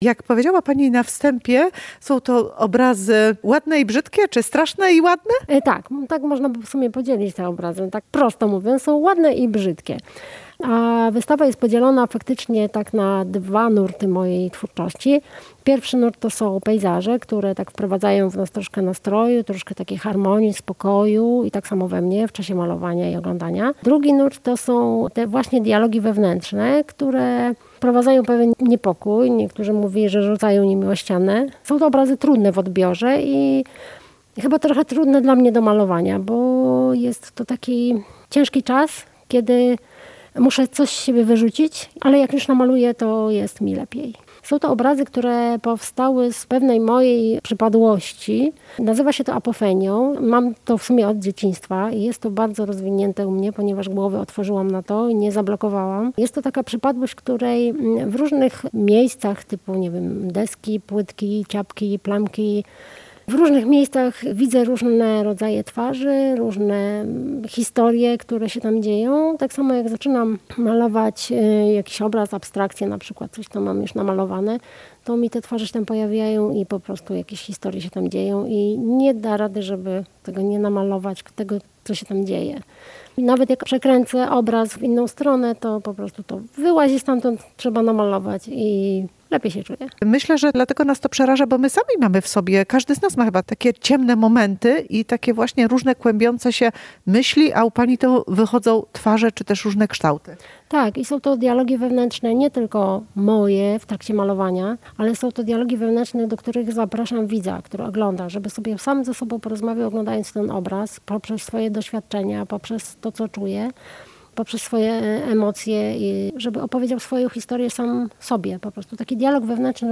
w rozmowie malarki